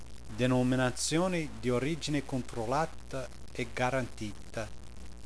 Italian Wine Pronunciation Guide
Click on a speaker symbol to hear the word spoken aloud.